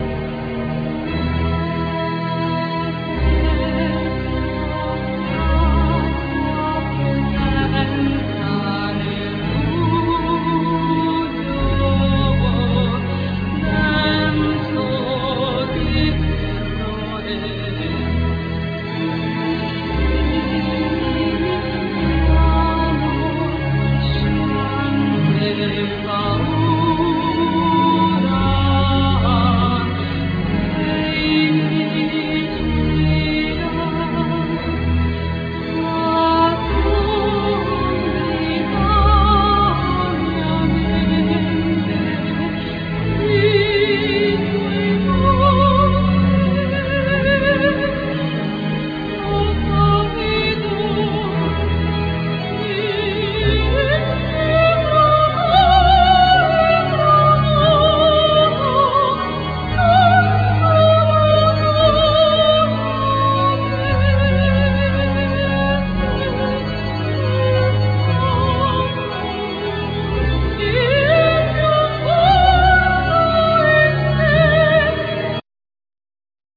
Voice,Keyboards,Percussions
Flute
Violin
Oboe
Guitar
Drums